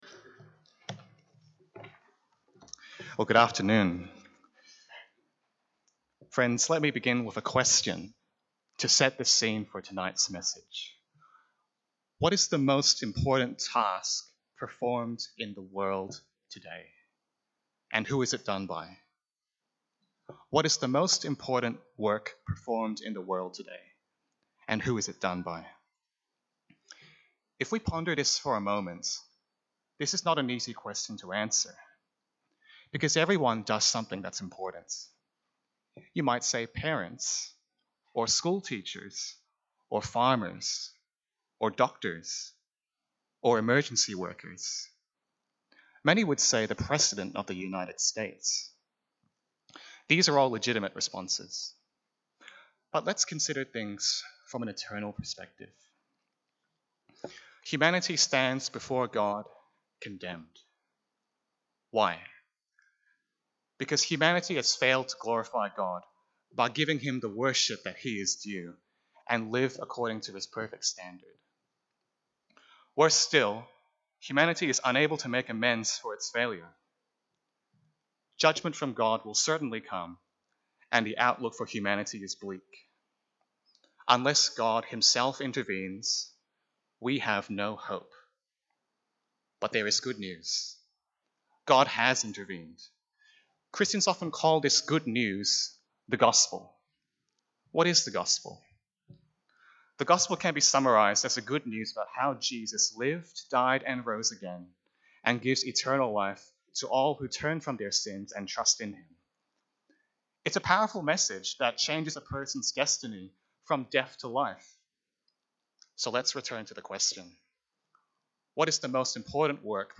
Service Type: Sunday Afternoon